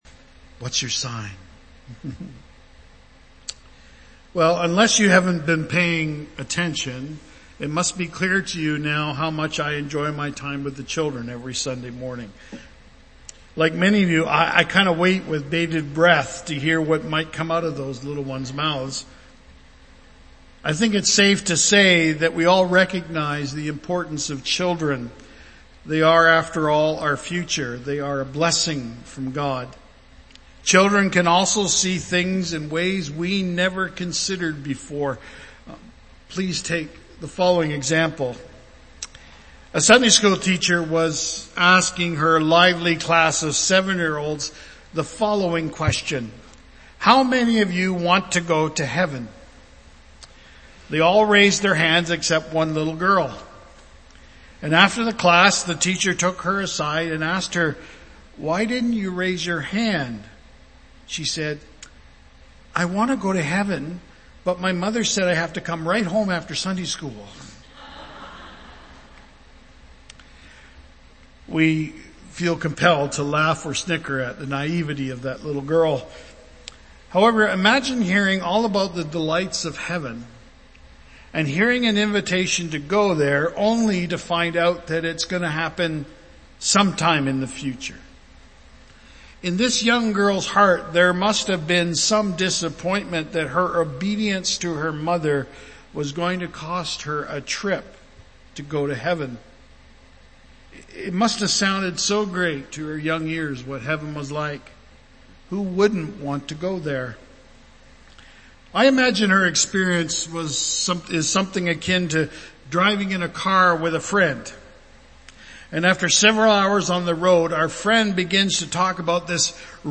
Archived Sermons